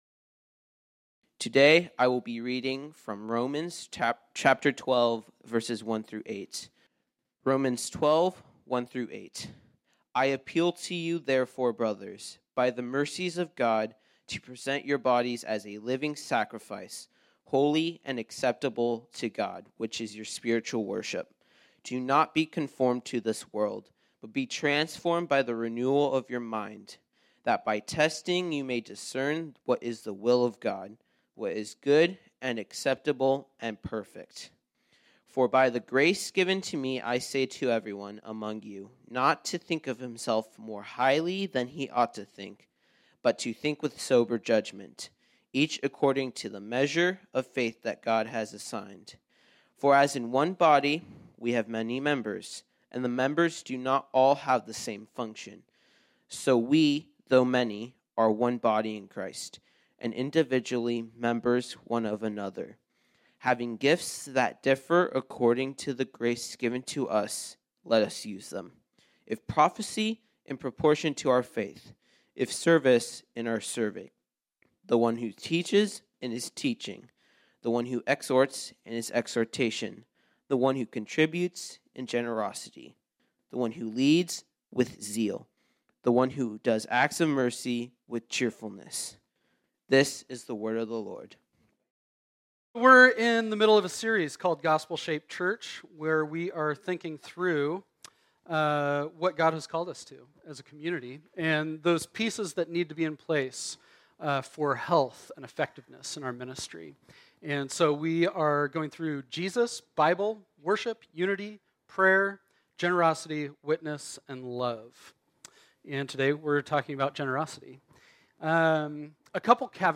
This sermon was originally preached on Sunday, February 27, 2022.